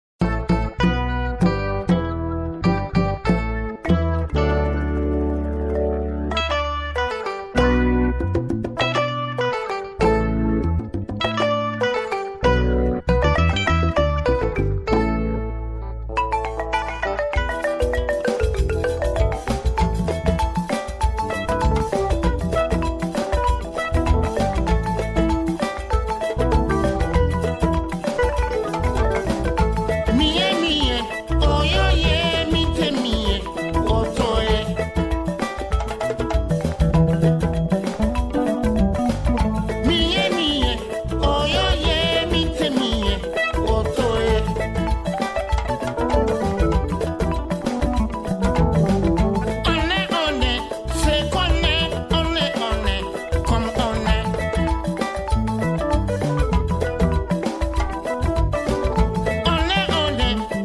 son de remix